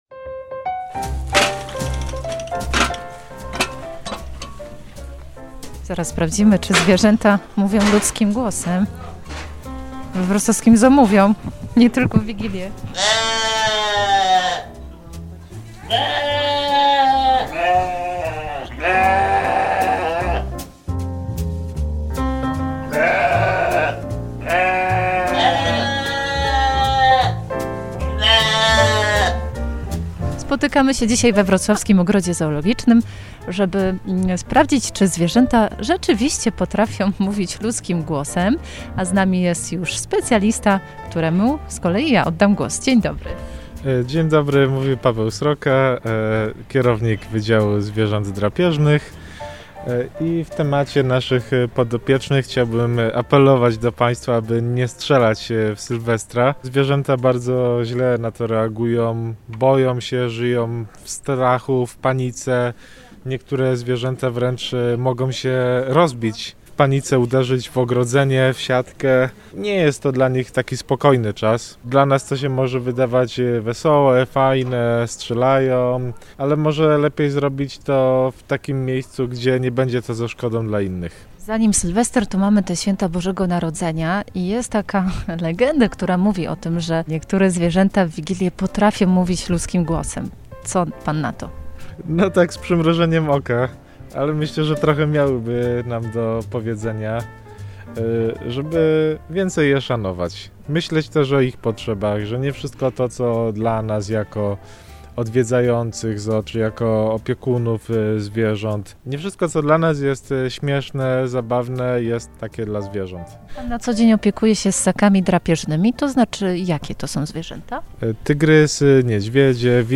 Sprawdzimy to w ZOO Ogrodzie Zoologicznym we Wrocławiu.